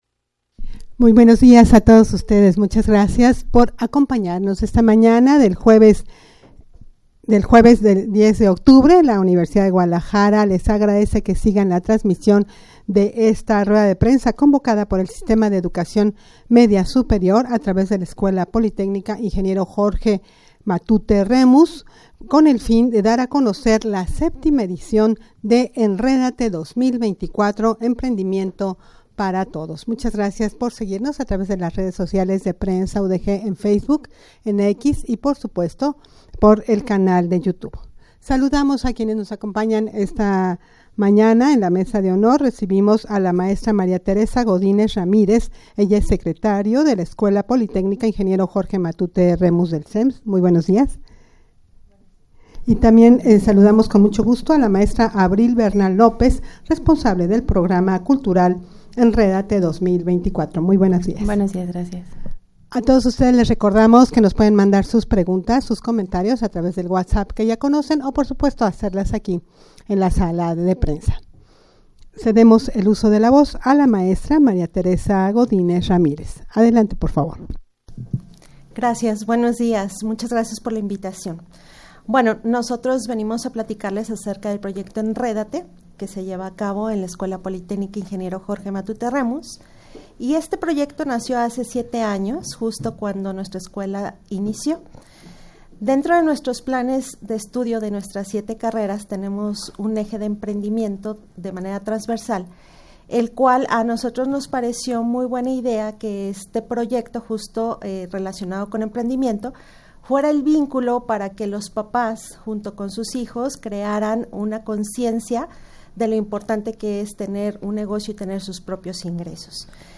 rueda-de-prensa-para-dar-a-conocer-el-evento-la-7ma-edicion-de-enredate-2024-emprendimiento-para-todos.mp3